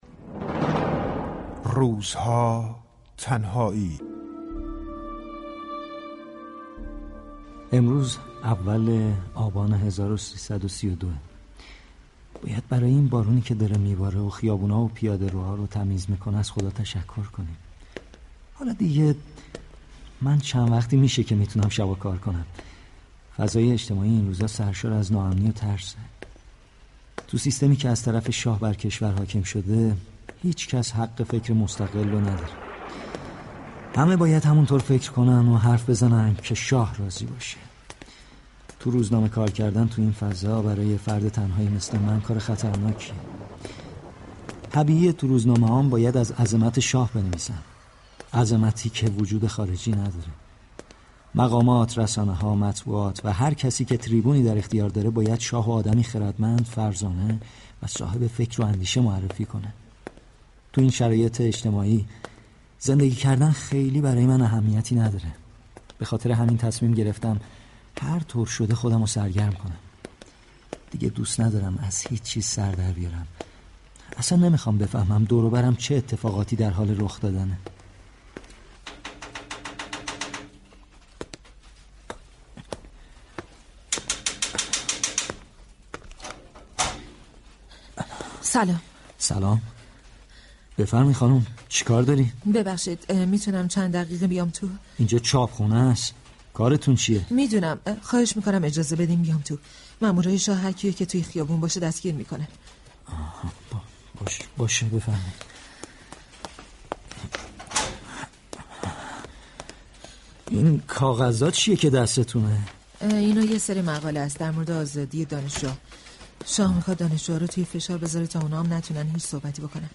پنج شنبه 16 آذرماه نمایش رادیویی «روزها، تنهایی» به مناسبت روز دانشجو از رادیو نمایش پخش می شود.